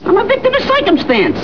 (audio source file... famous quote from The Three Stooges)